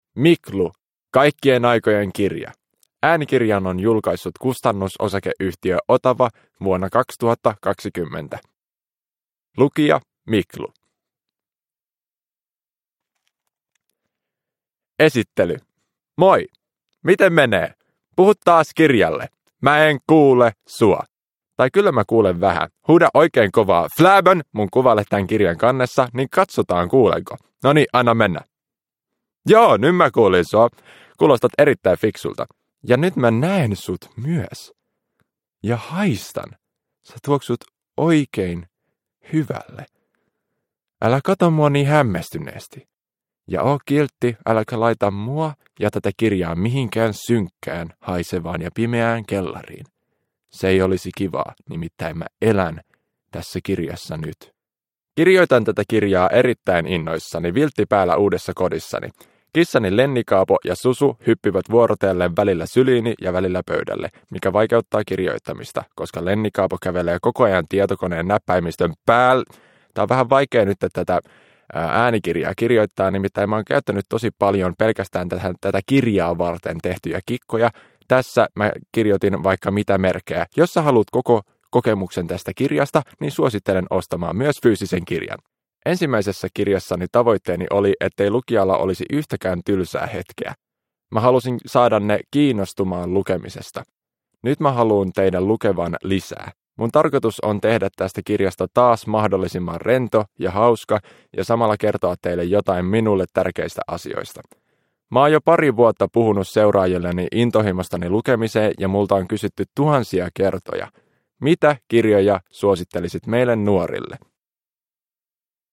Kaikkien aikojen kirja – Ljudbok – Laddas ner